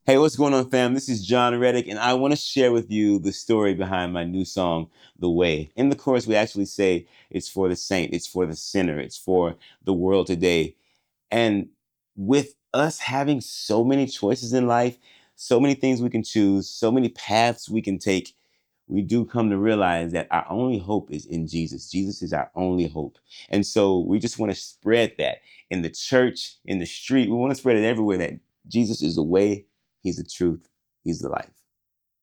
SBTS-NO-MUSIC-AUDIO-ONLY.wav